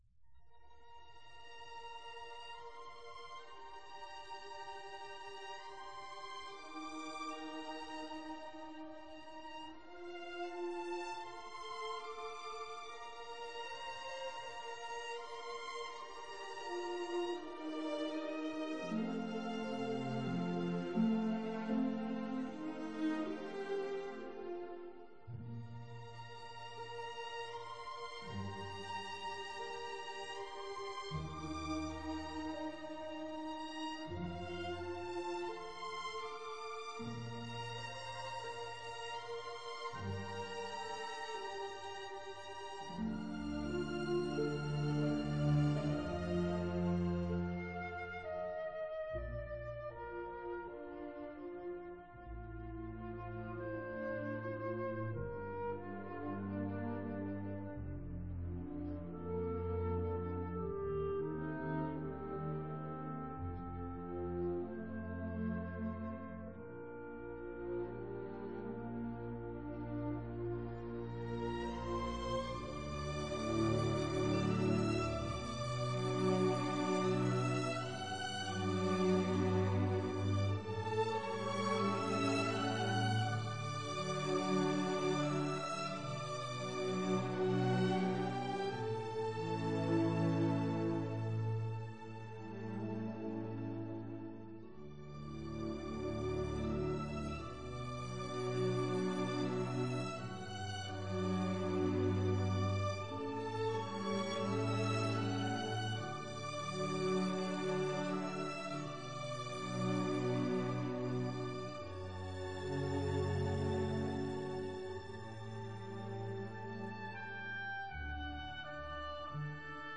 奔放的旋律